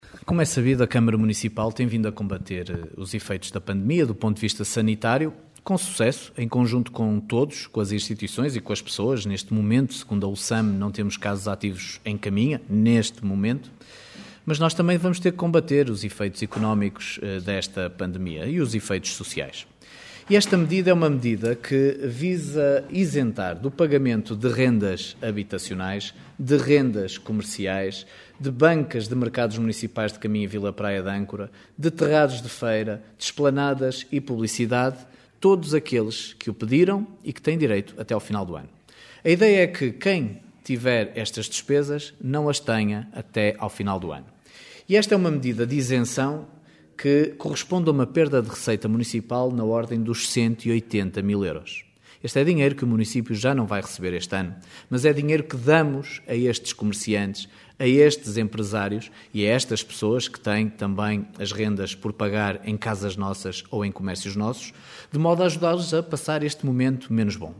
O objetivo é minimizar os efeitos da pandemia a nível económico como explica Miguel Alves.